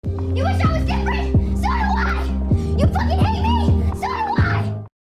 rawr sound effects free download